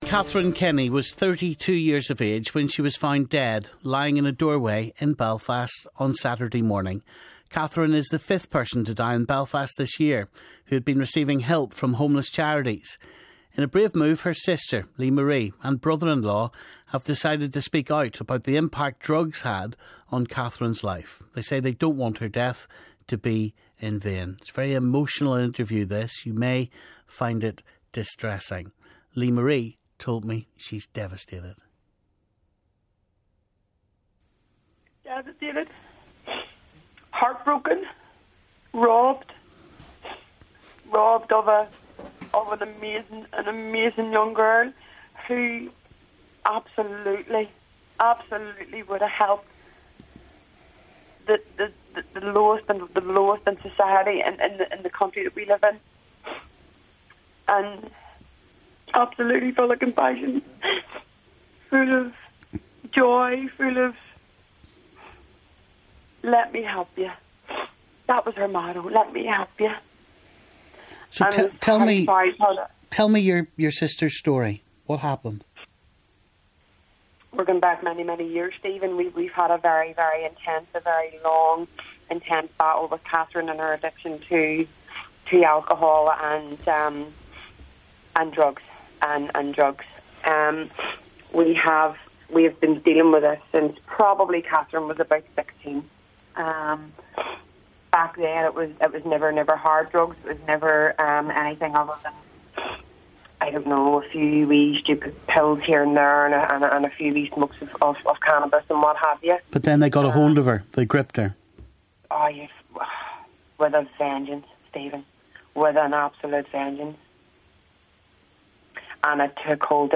Some listeners may find this interview upsetting.